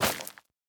Minecraft Version Minecraft Version 1.21.5 Latest Release | Latest Snapshot 1.21.5 / assets / minecraft / sounds / block / netherwart / break1.ogg Compare With Compare With Latest Release | Latest Snapshot
break1.ogg